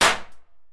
ctf_ranged_pistol.ogg